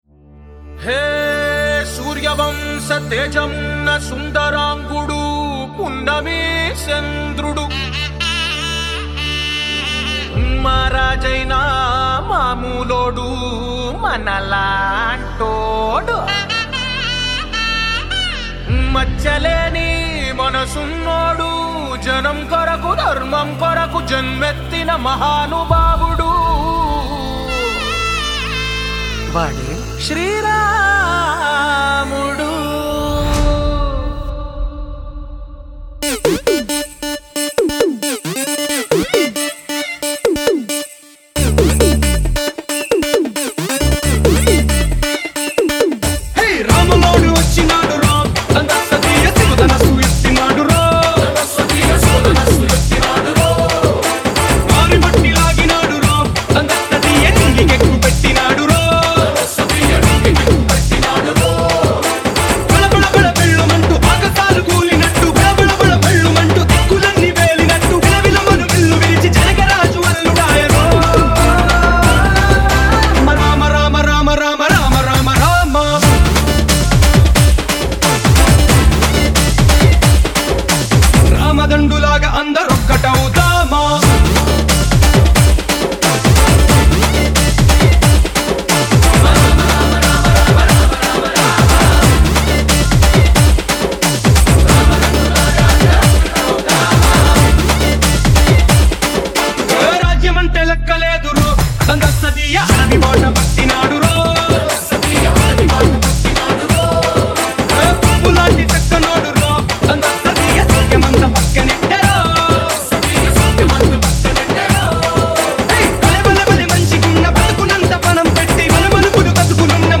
Official Club Mix